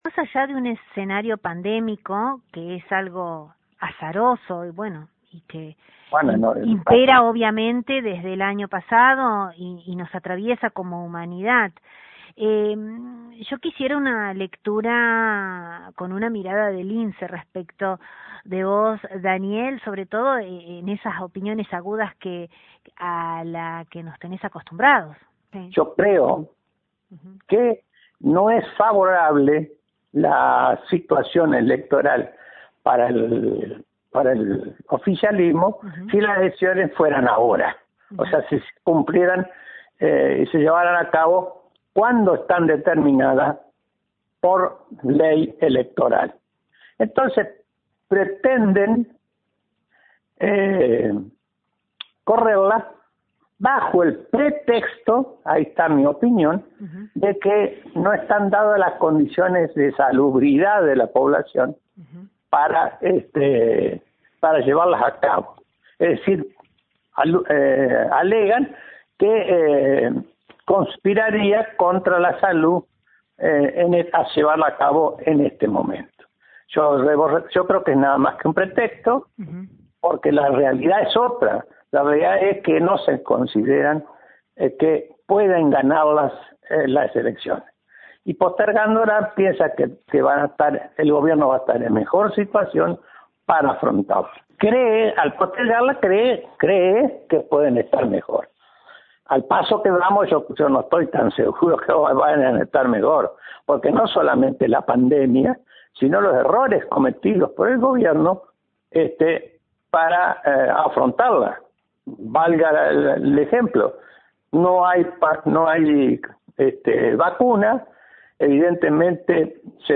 Desde LT39 NOTICIAS, dialogamos con el Doctor Daniel Sobrero, ex legislador provincial, mandato cumplido y prestigioso integrante del foro local; amén de ser un fiel representante del centenario partido.